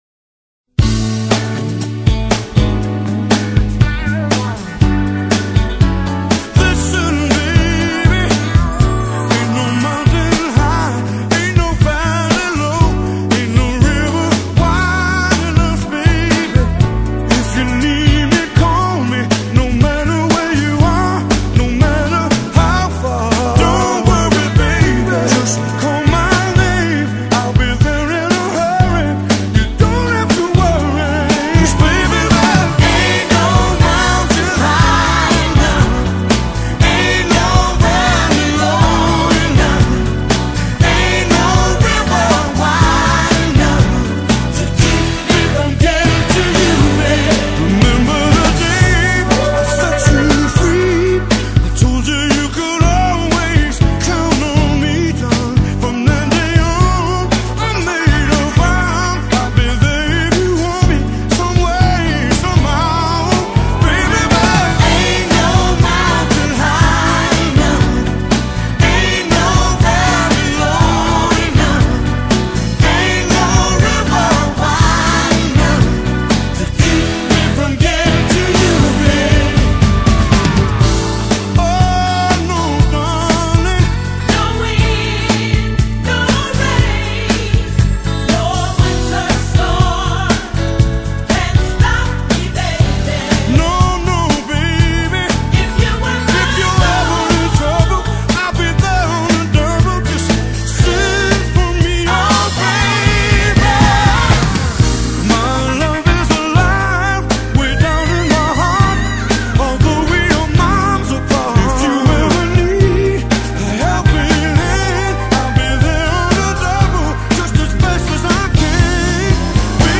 in D, original key